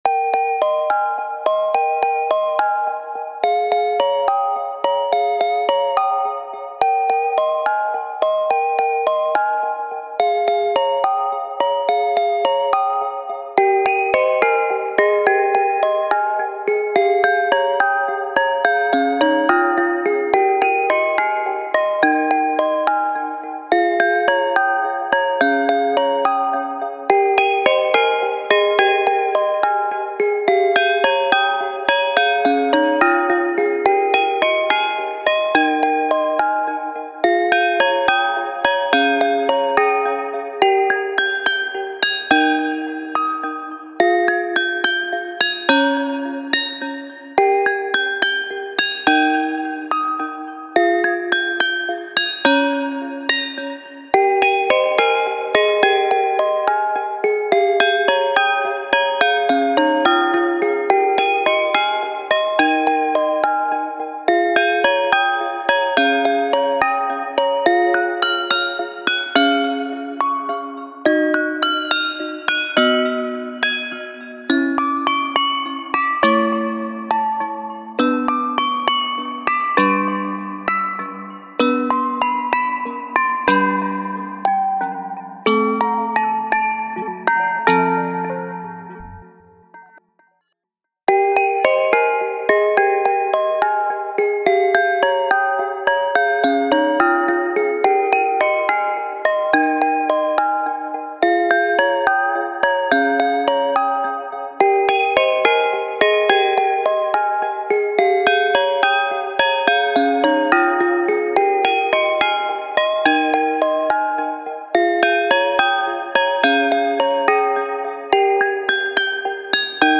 タグ: Chill ほのぼの/穏やか 幻想的 コメント: 閉じられたガラス瓶の中の世界をイメージしたBGM。